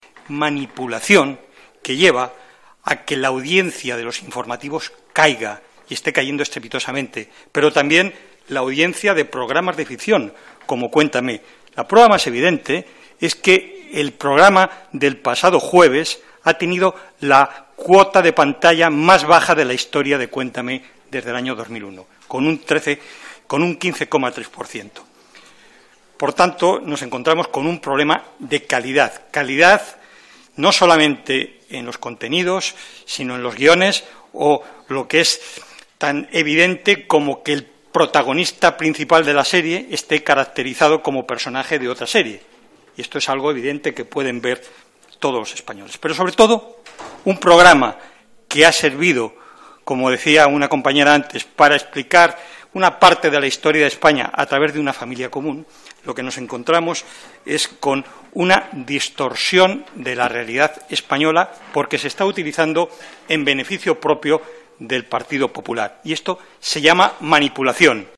Comisión mixta de control de RTVE.